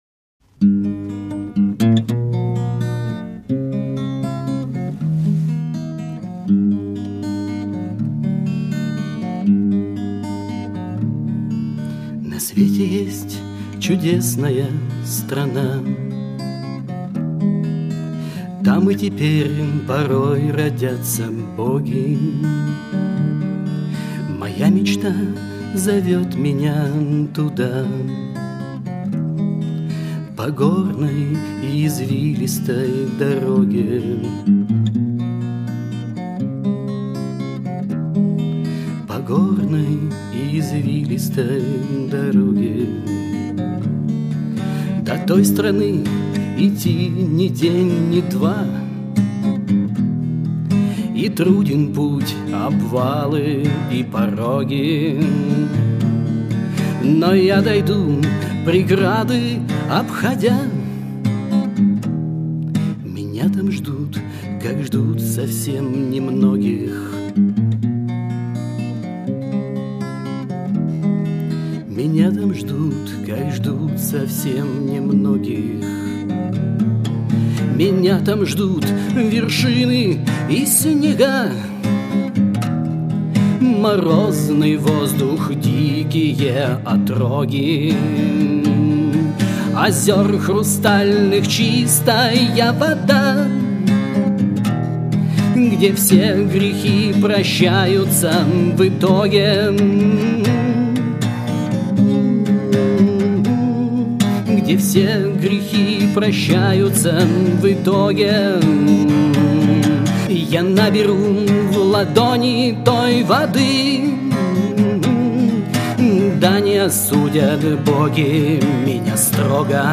Армейские и дворовые песни под гитару
Кавер-версия песни